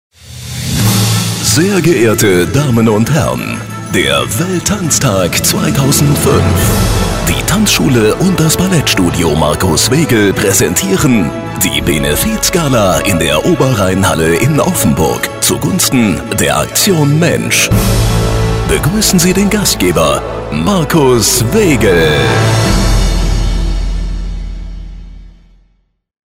Showansage
Unterlegt mit der passenden Musik wird Ihr Event garantiert ein Volltreffer!